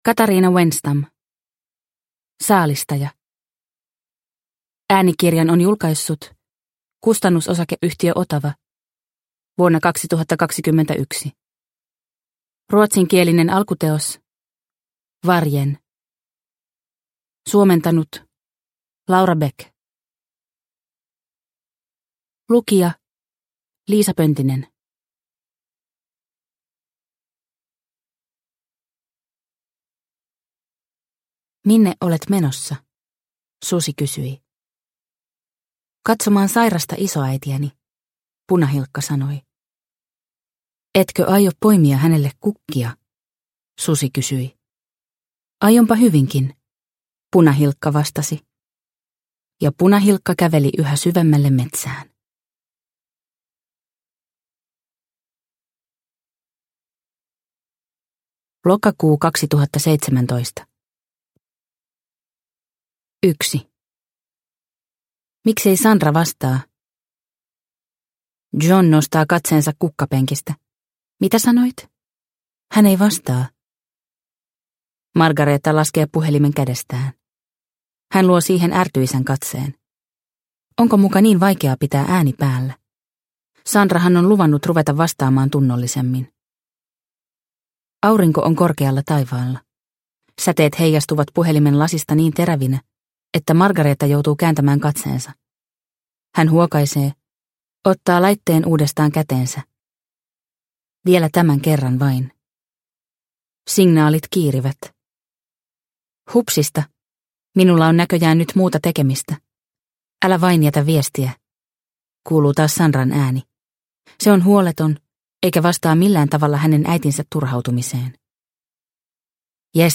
Saalistaja – Ljudbok – Laddas ner